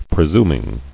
[pre·sum·ing]